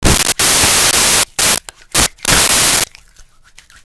Soap In Hands 1
soap-in-hands-1.wav